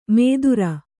♪ mēdura